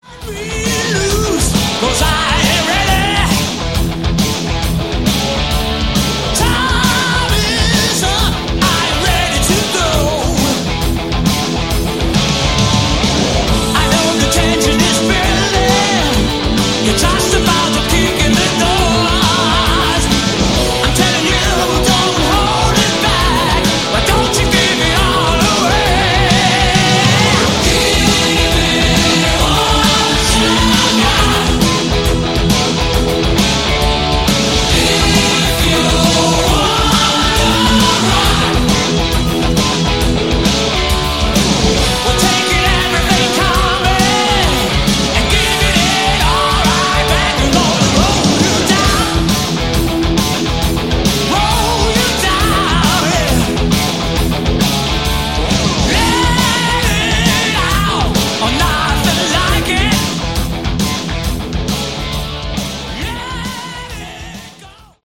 Category: Melodic Hard Rock
guitars, lead vocals, bass
backing vocals, keyboards
drums